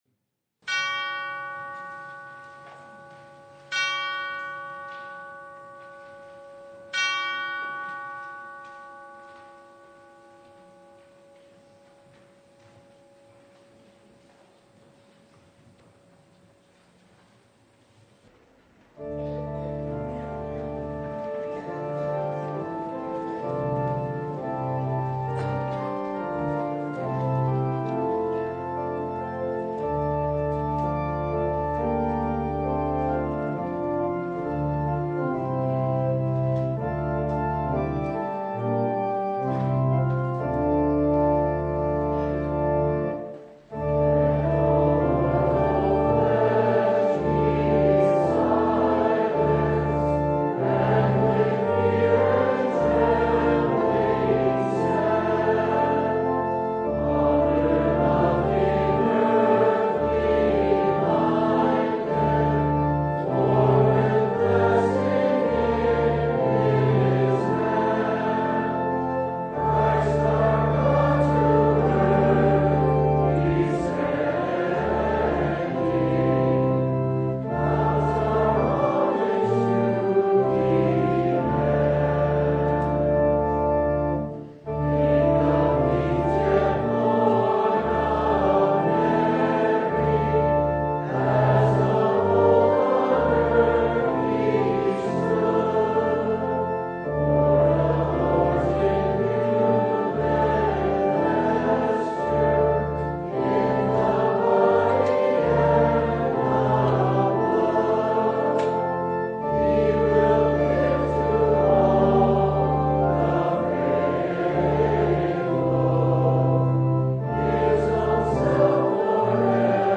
On this, the Last Sunday of the Church Year, we celebrate Christ our King, seated on the throne at His Ascension and coming soon in glory.
Preacher: Visiting Pastor Passage: Mark 13:24-37